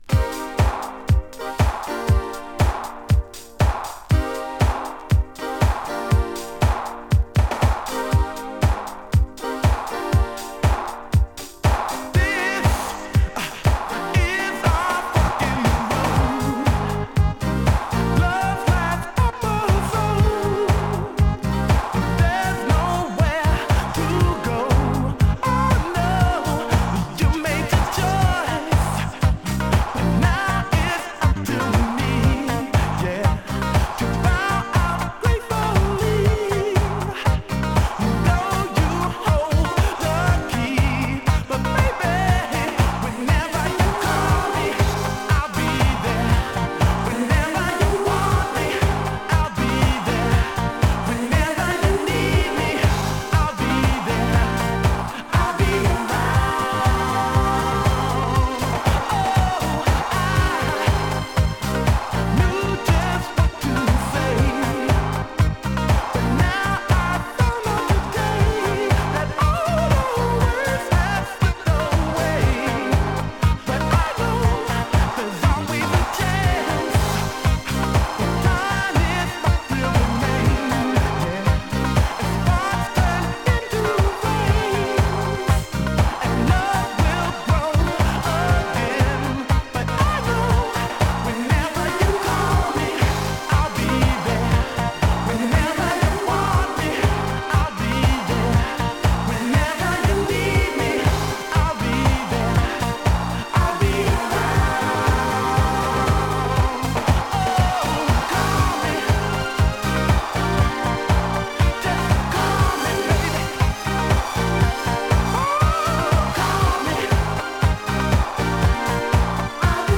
【DISCO】